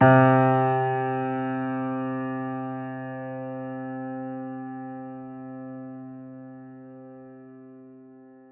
piano-sounds-dev
Vintage_Upright
c2.mp3